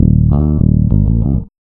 SPD140BASS-L.wav